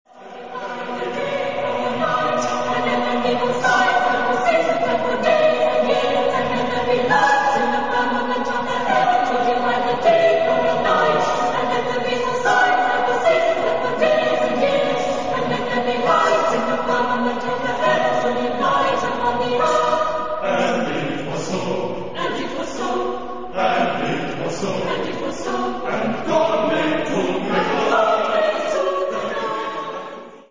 Genre-Style-Form: Sacred ; Contemporary
Type of Choir: SATB  (4 mixed voices )
Soloist(s): Soprano (1)  (1 soloist(s))
Instruments: Piano (1)